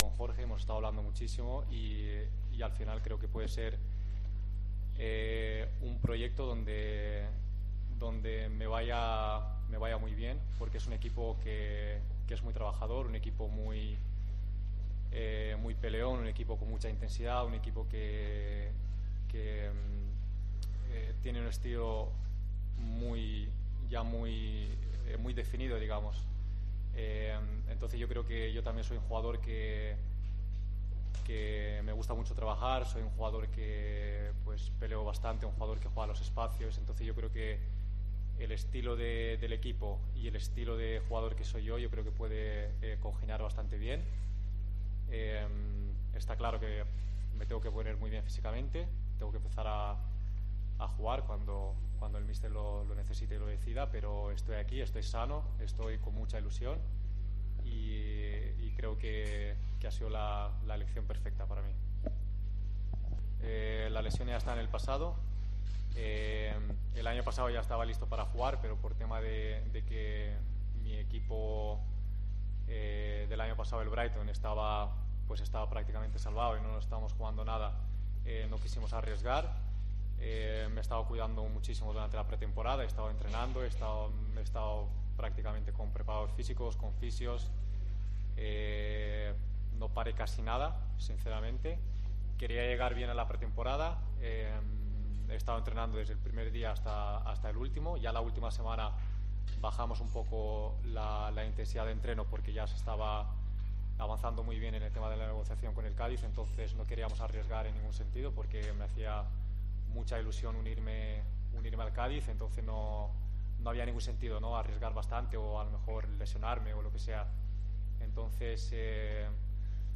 Florín Andone habla como jugador del Cádiz